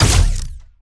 Plasmid Machinegun
GUNAuto_Plasmid Machinegun Single_03_SFRMS_SCIWPNS.wav